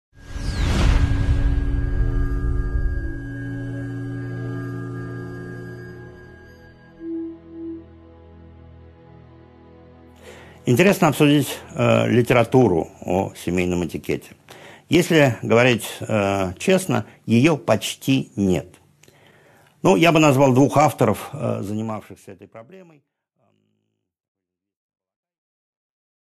Аудиокнига 7.3 Литература о семейном этикете | Библиотека аудиокниг